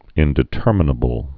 (ĭndĭ-tûrmə-nə-bəl)